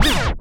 scratch10.wav